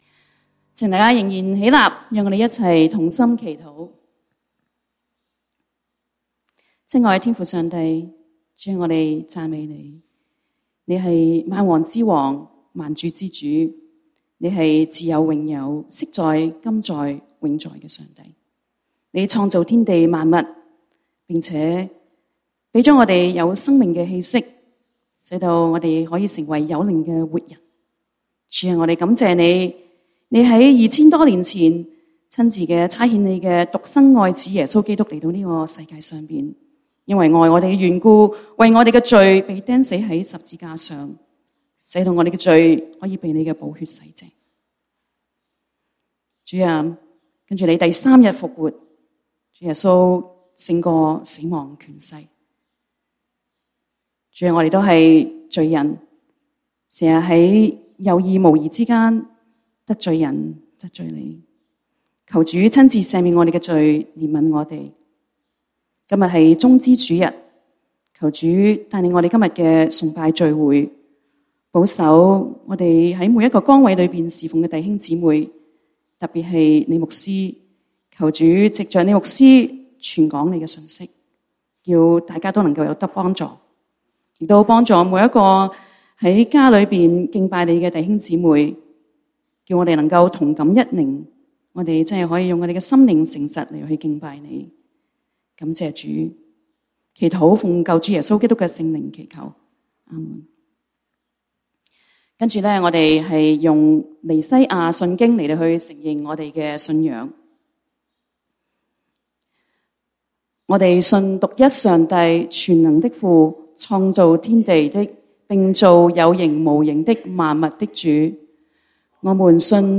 粵語堂主日崇拜 講道經文：《馬太福音》Matthew 21:1-11 本週箴言：《詩篇》Psalms 95:1-3 「來啊，我們要向耶和華歌唱，向拯救我們的磐石歡呼！